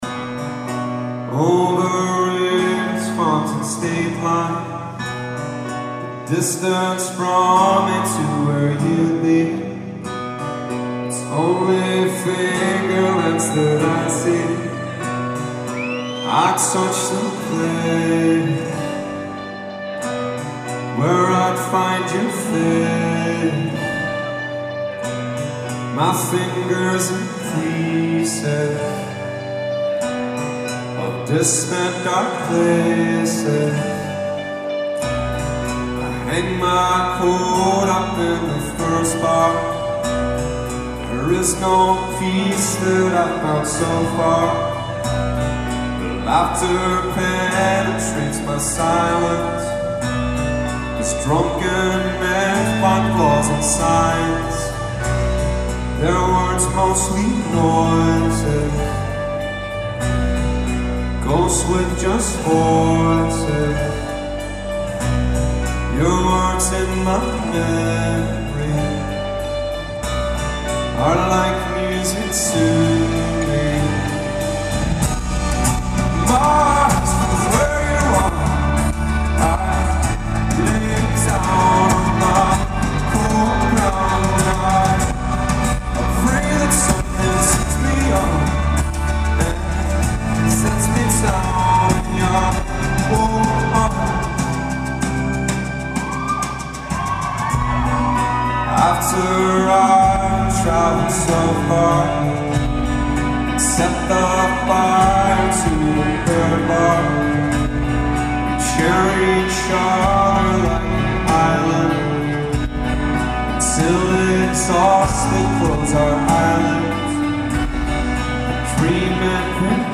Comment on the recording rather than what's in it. I hit record (a little late) for this song.